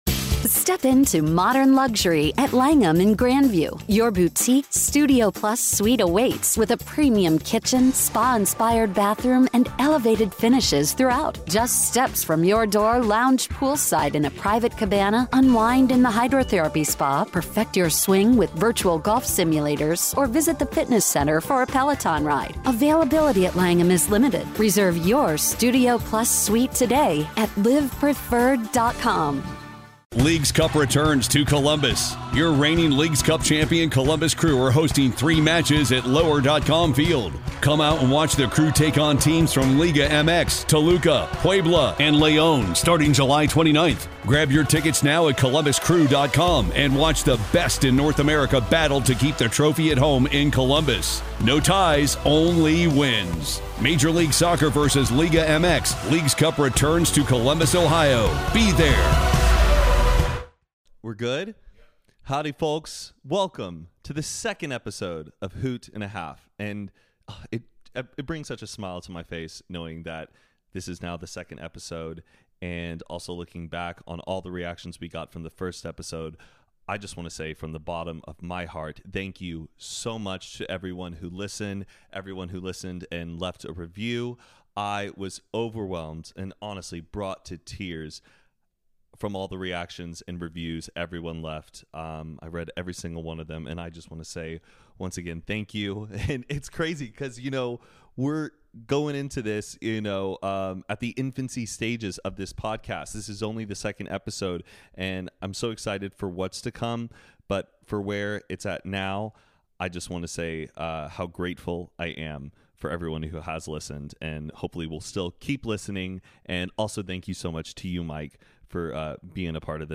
Jason Nash (Youtube, Views w/ David Dobrik, FML) is an American comedian, writer, and internet personality. Jason chats about his days growing up in Boston, working at SNL, doing stand-up comedy and the joys of being a father.